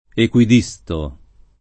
ekUid&Sto] — in uso, praticam., solo il part. pres. equidistante [ekUidiSt#nte], con valore di agg., e il suo astratto equidistanza [